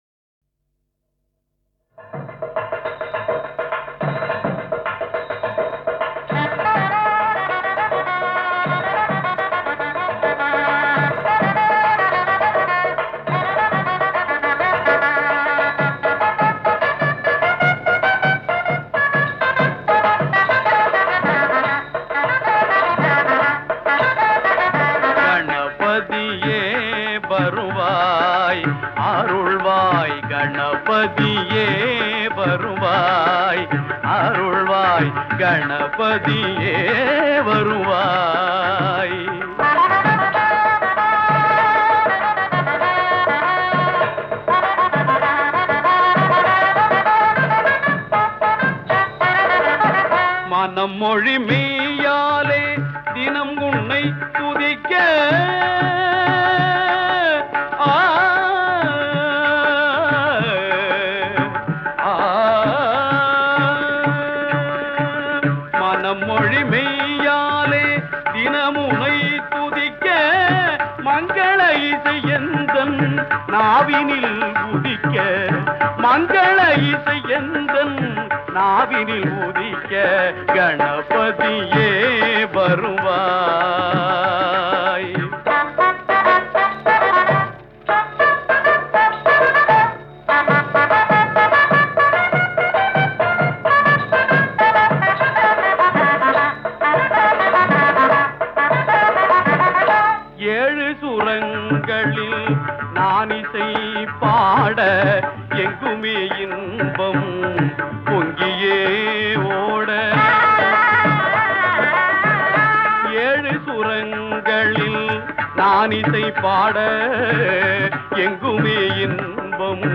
Vinayagar Song